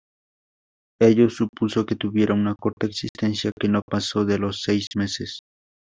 Read more (feminine) existence (feminine, in-plural) stock; inventory Frequency B2 Hyphenated as e‧xis‧ten‧cia Pronounced as (IPA) /eɡsisˈtenθja/ Etymology Borrowed from Late Latin exsistentia.